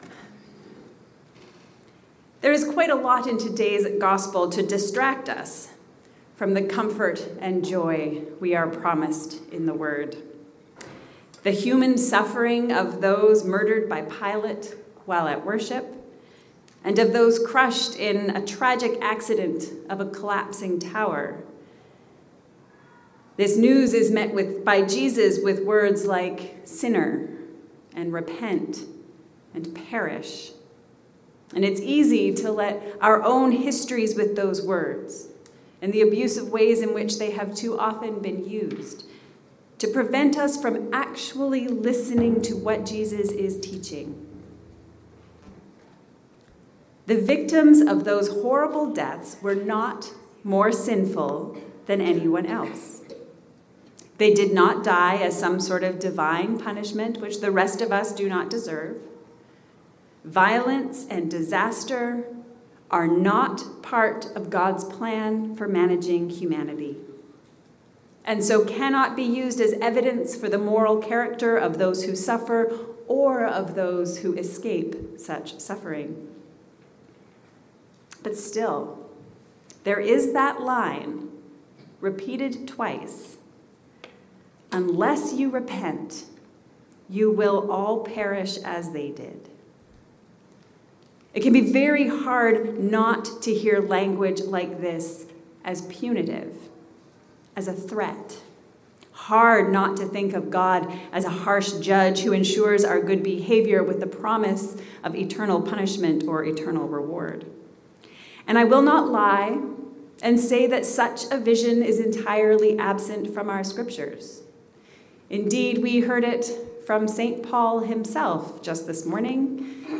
Repent and Bear Fruit. A Sermon for the Third Sunday in Lent